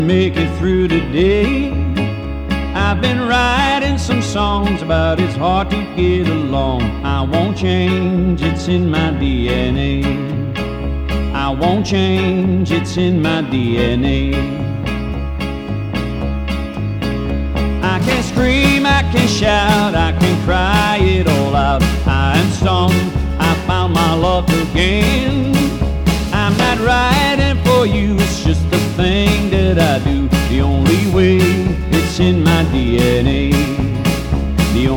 Жанр: Рок / Русские
# Rock & Roll